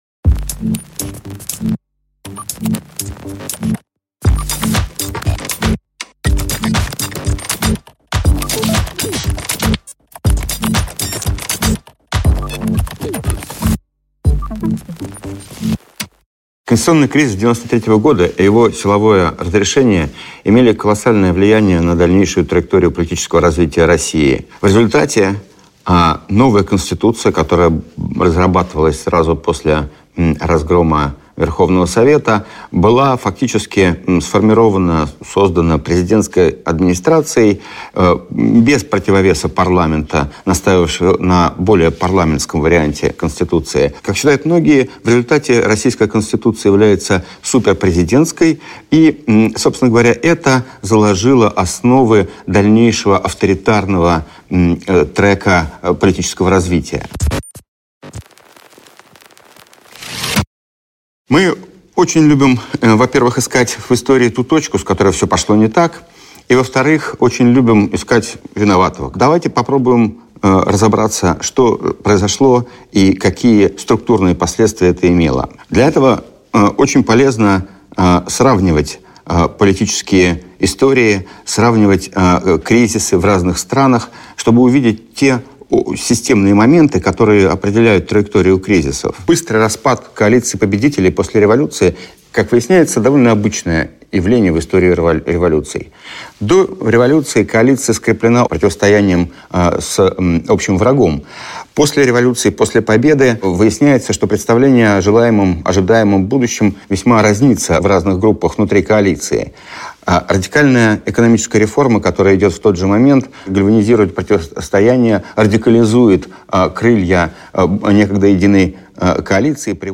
Aудиокнига Система-93 Автор Кирилл Рогов.